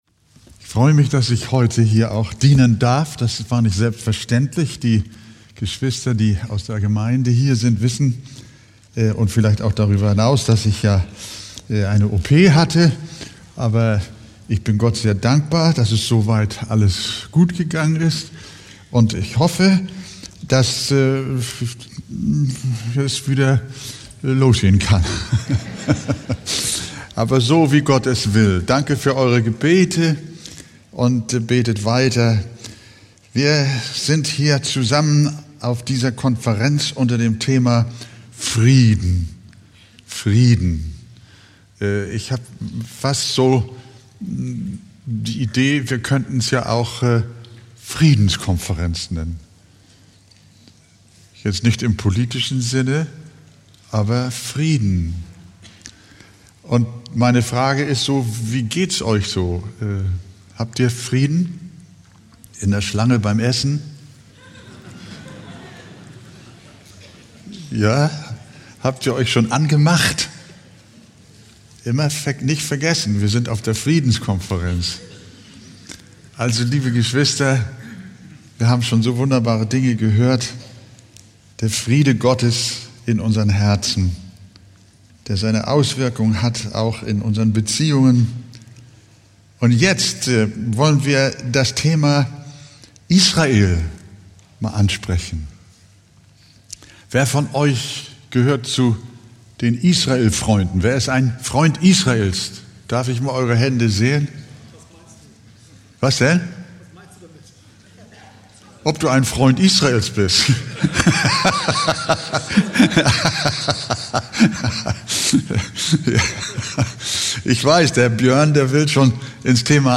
Session-4 Eckstein-Konferenz 2025.mp3